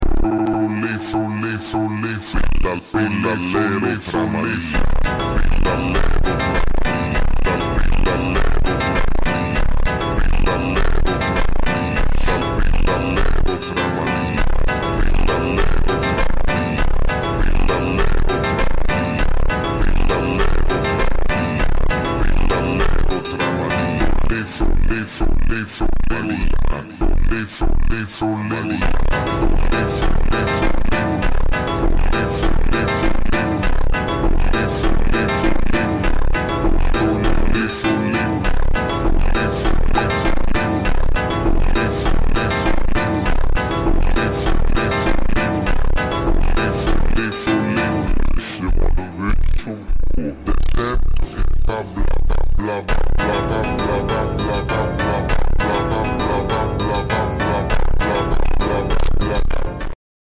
Samsung Galaxy A34 One UI Sound Effects Free Download